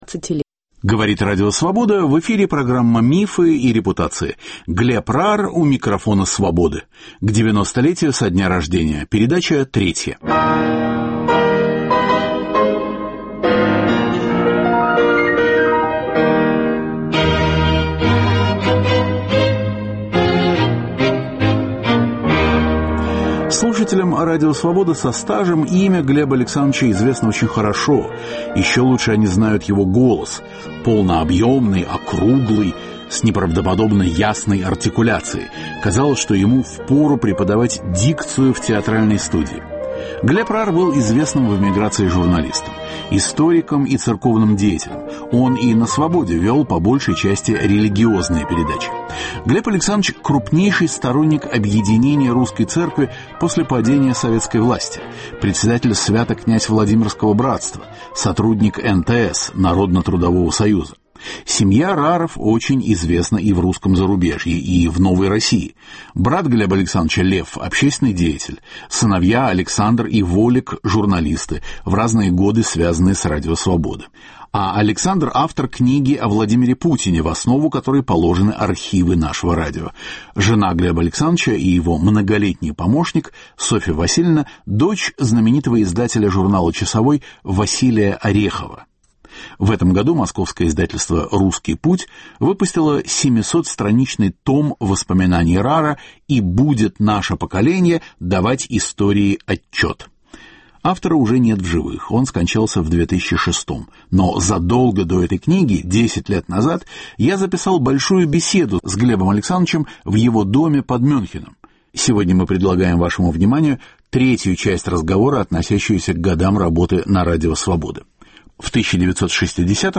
Беседа записана в 2002 году.